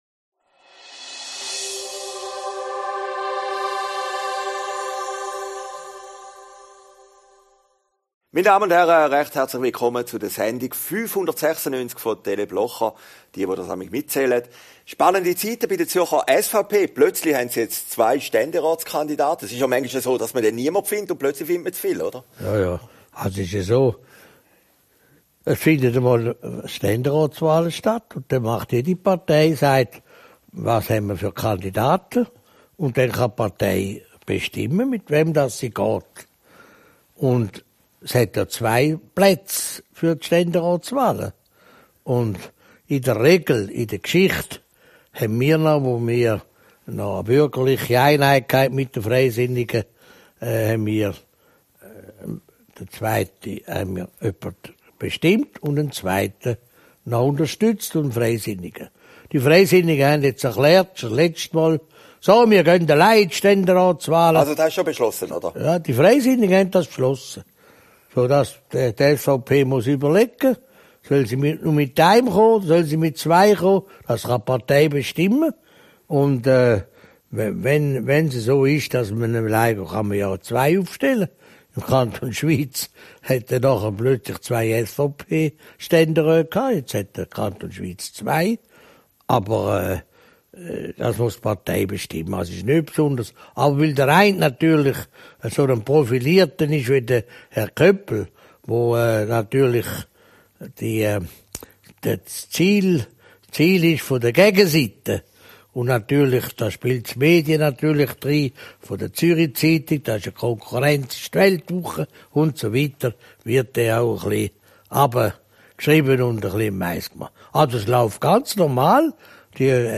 Aufgezeichnet in Herrliberg, 1. Februar 2019